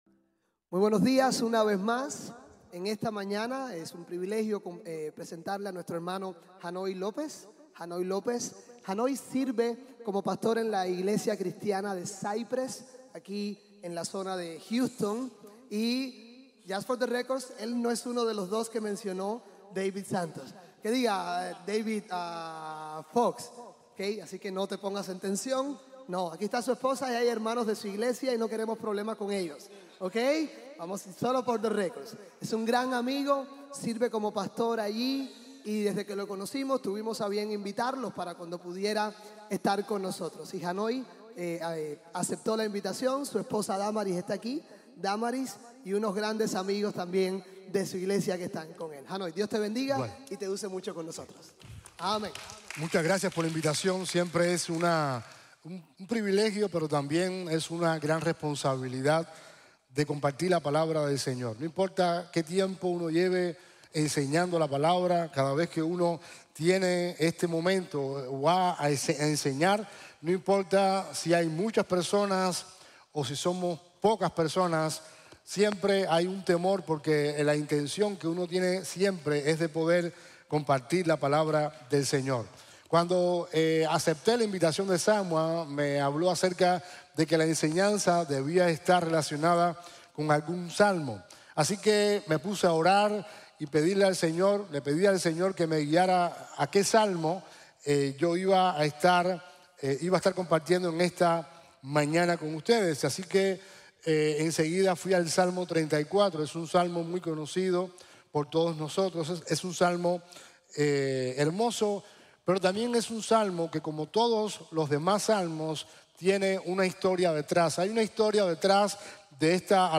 Salmo 34 | Sermon | Grace Bible Church